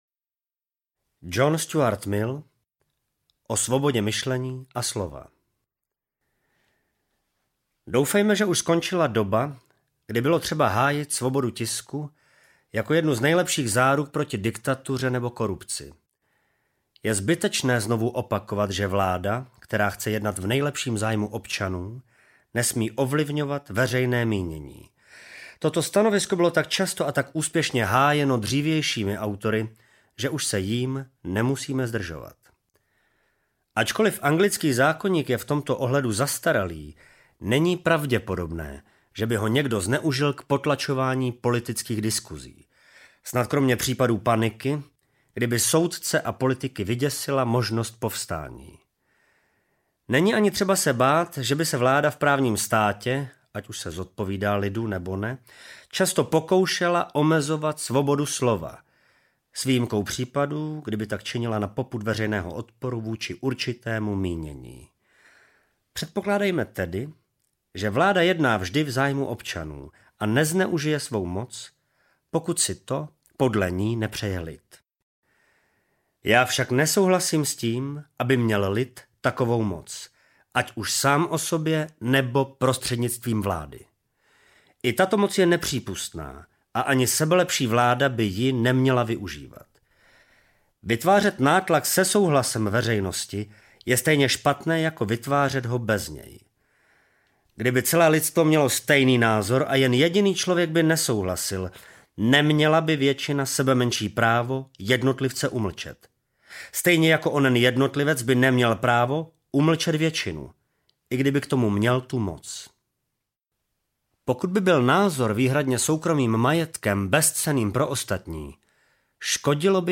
O svobodě myšlení a slova audiokniha
Ukázka z knihy